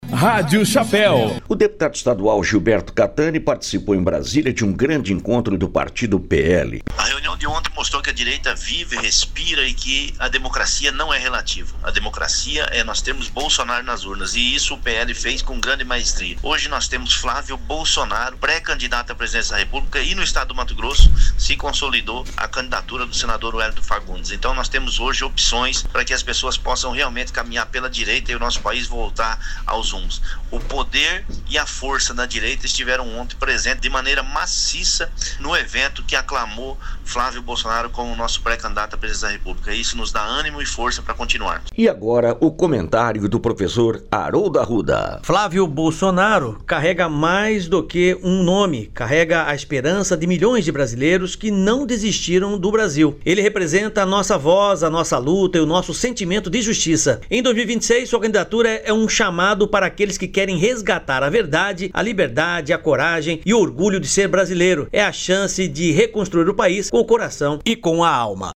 OUÇA O ÁUDIO DO DEPUTADO CATTANI SOBRE REUNIÃO DO PL